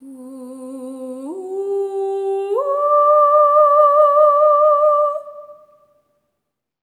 ETHEREAL07-L.wav